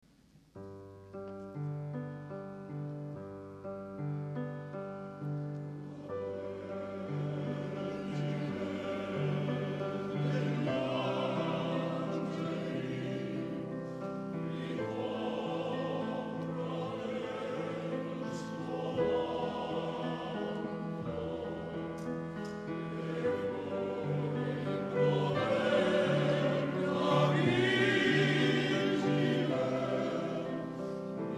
I Brani Musicali sono stati registrati al Teatro "A. Bonci" di Cesena il 18 Febbraio 2001 durante il
CONCERTO LIRICO
CORALE BANDISTICO
Il Coro Lirico Città di Cesena
Banda "Città di Cesena"